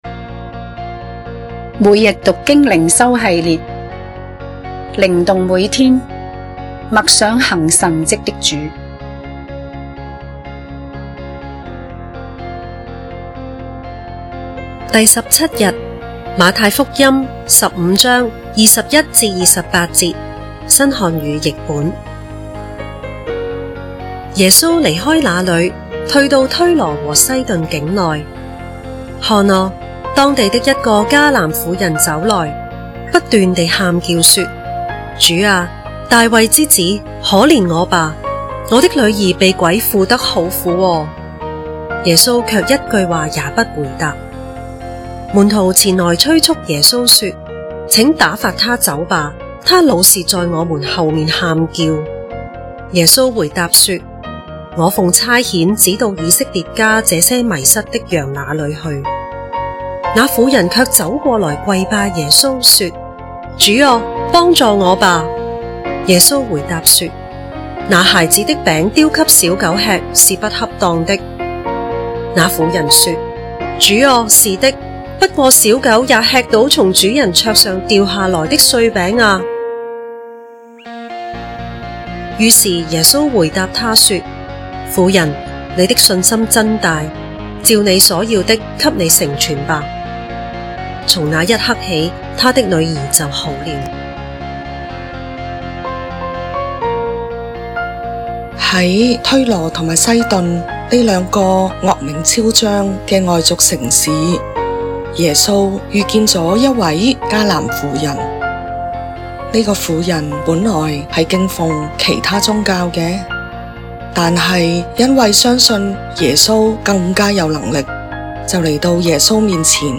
經文閱讀